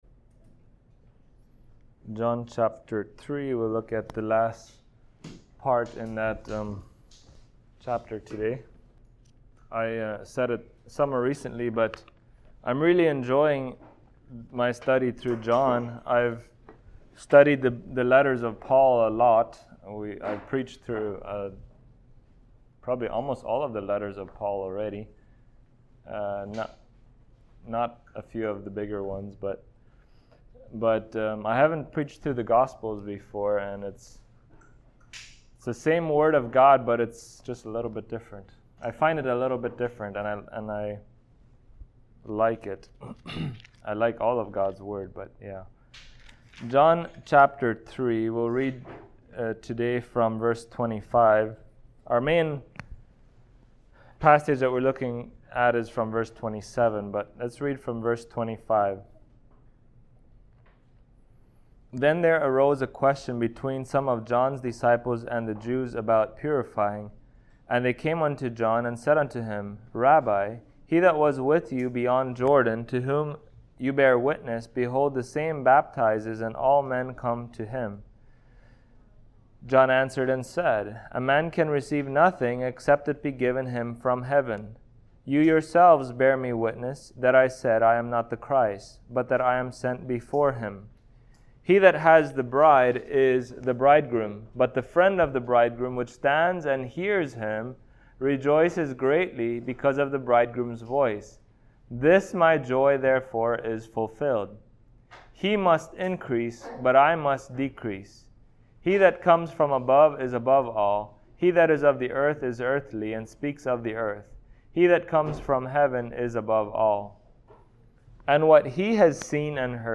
John Passage: John 3:25-36 Service Type: Sunday Morning Topics